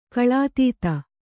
♪ kaḷātīta